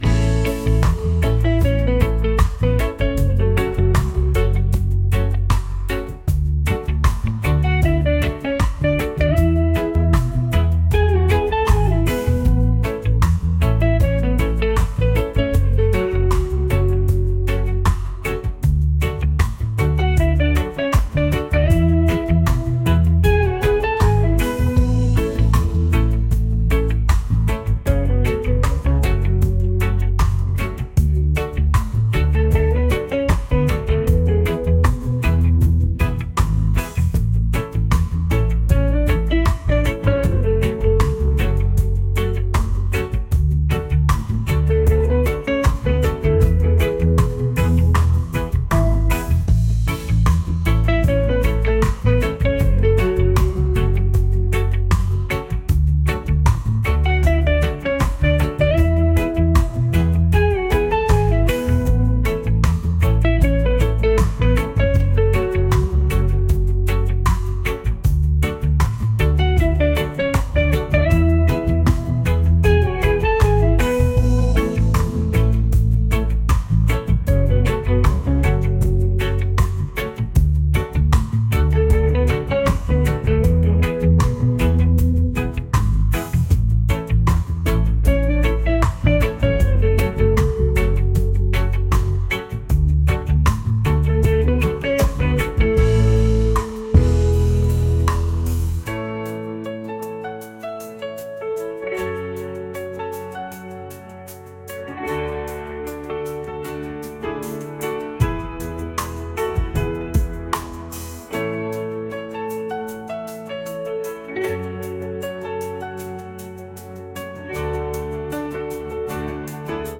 reggae | lofi & chill beats | ambient